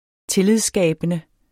Udtale [ ˈteliðsˌsgæˀbənə ]